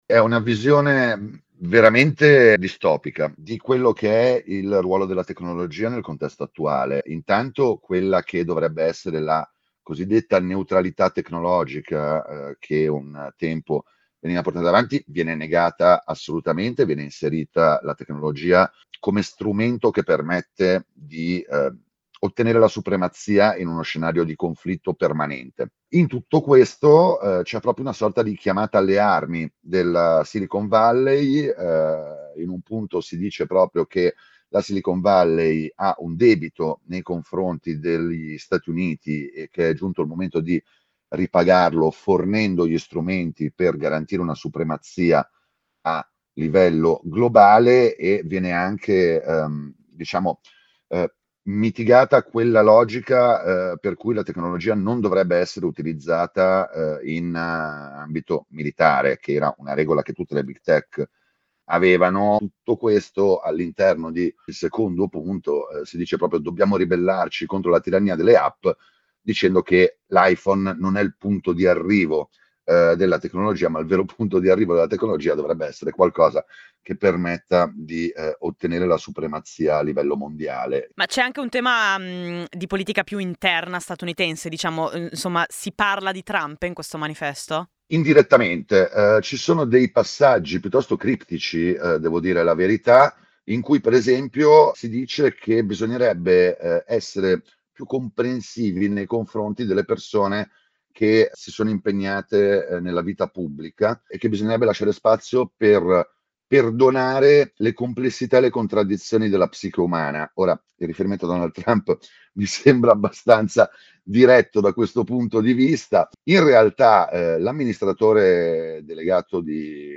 Ne abbiamo parlato con il nostro collaboratore